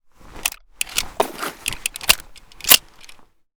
aps_reload_empty.ogg